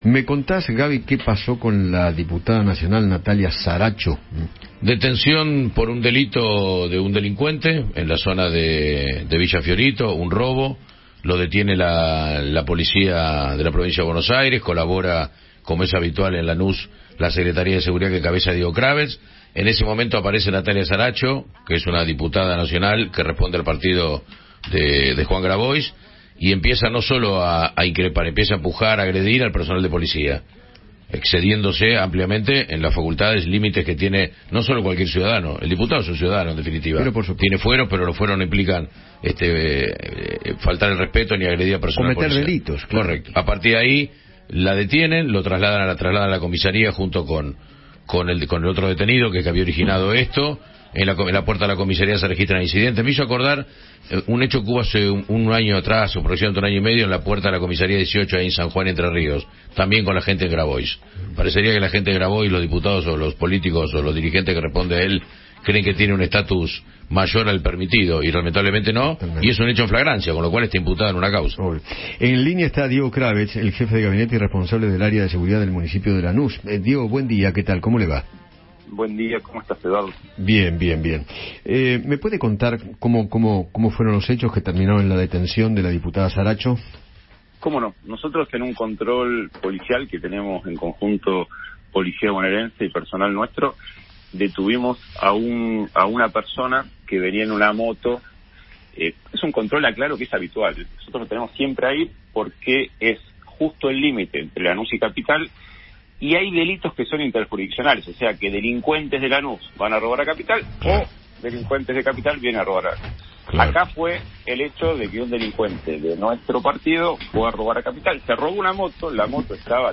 Diego Kravetz, jefe de gabinete de Lanús, conversó con Eduardo Feinmann sobre la detención de la diputada nacional, Natalia Zaracho, en aquella localidad, tras un altercado con la policía bonaerense.